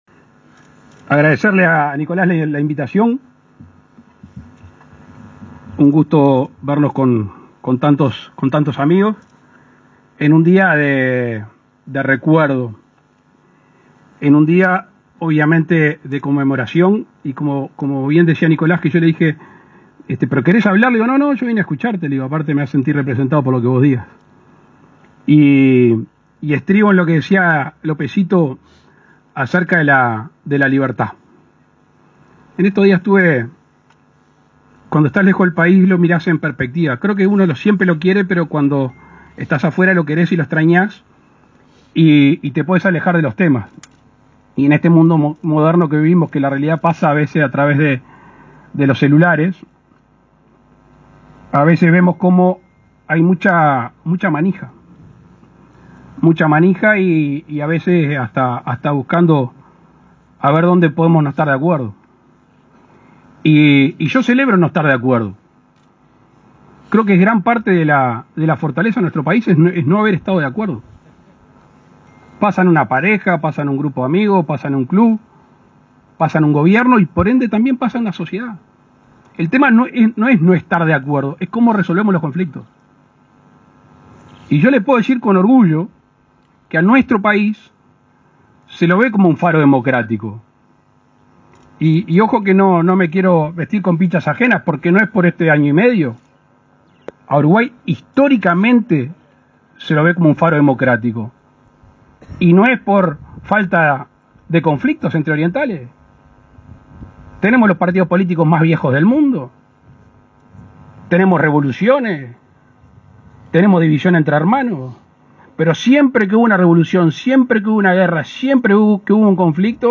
Palabras del presidente de la República, Luis Lacalle Pou
Palabras del presidente de la República, Luis Lacalle Pou 26/09/2021 Compartir Facebook Twitter Copiar enlace WhatsApp LinkedIn Este 26 de setiembre, autoridades nacionales y departamentales homenajearon al Gral. José Gervasio Artigas, en la meseta denominada con el nombre del prócer, en Paysandú. El presidente Lacalle Pou participó del evento.